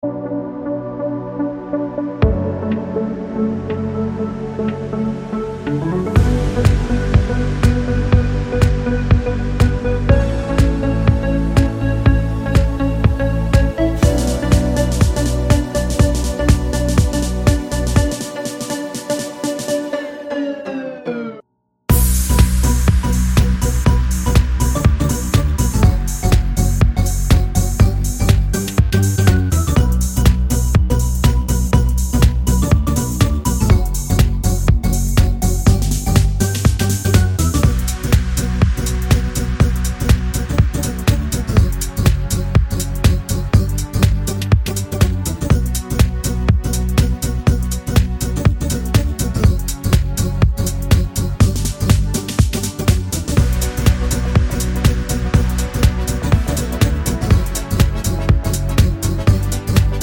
no Backing Vocals Finnish 3:28 Buy £1.50